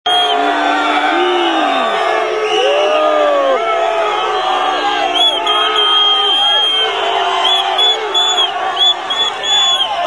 At the Dublin Car-free day demo, Police broke up the celebration of freedom from carbon monoxopoly by forcefully removing people and bikes.